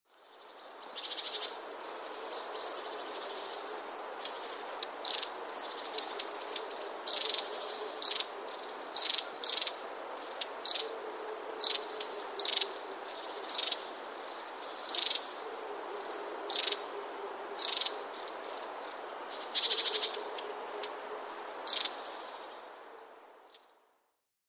Quizy "Poznaj ptaka po śpiewie"
Przed każdym formularzem umieszczony jest link do pliku z nagraniem śpiewu ptaka, który jest na pierwszym planie.